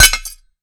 grenade_hit_metal_hvy_01.WAV